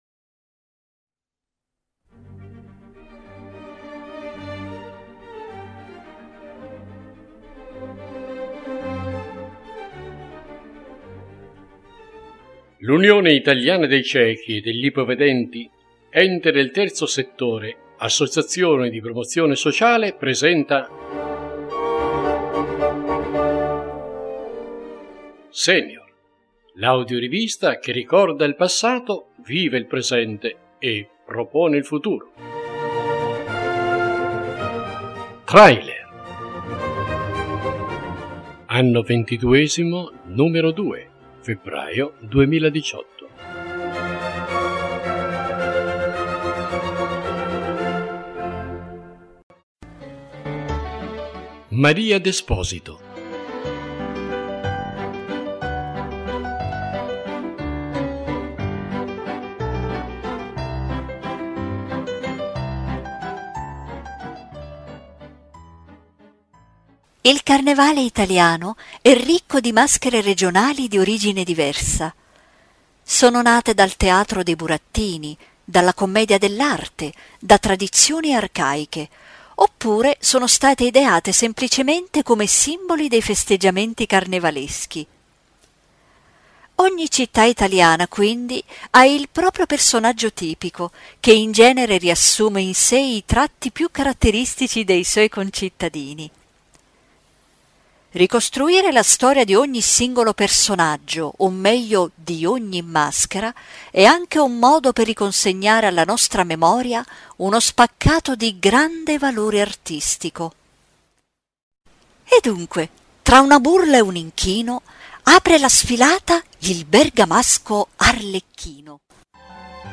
Questa è l’audiorivista che realizza il proprio slogan: ricorda il passato, vive il presente e propone il futuro.